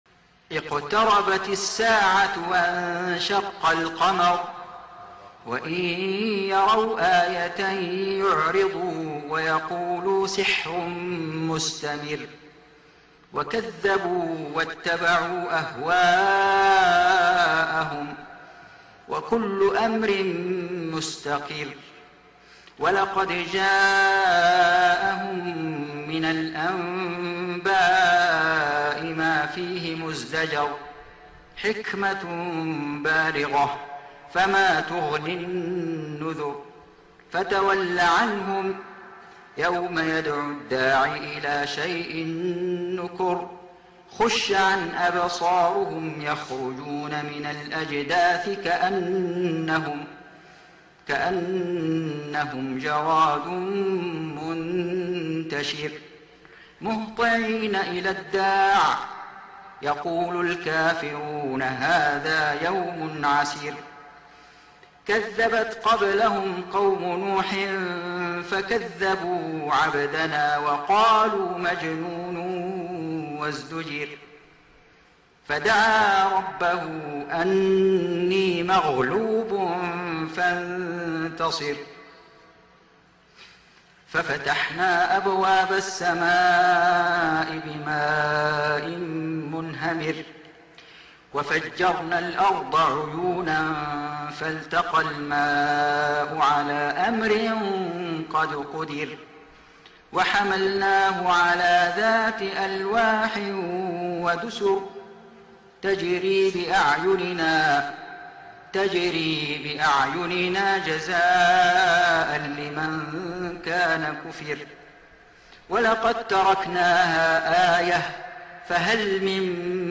سورة القمر > السور المكتملة للشيخ فيصل غزاوي من الحرم المكي 🕋 > السور المكتملة 🕋 > المزيد - تلاوات الحرمين